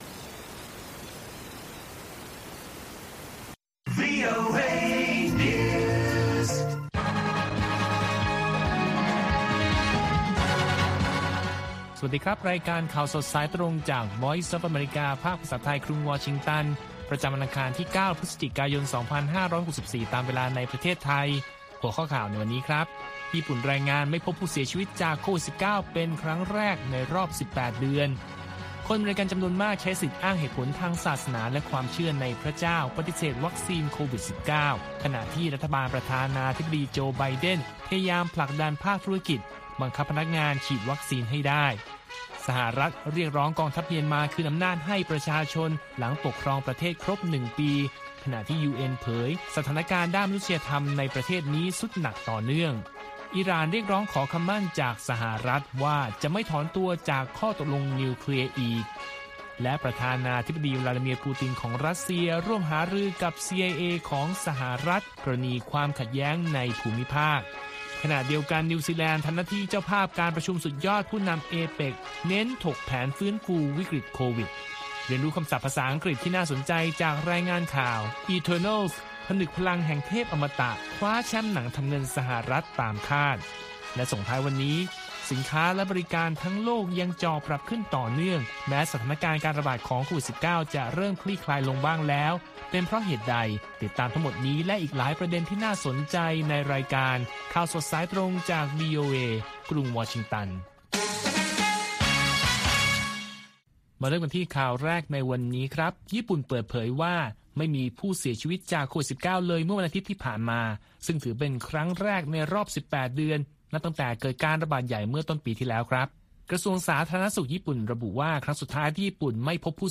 ข่าวสดสายตรงจากวีโอเอ ภาคภาษาไทย ประจำวันอังคารที่ 9 พฤศจิกายน 2564 ตามเวลาประเทศไทย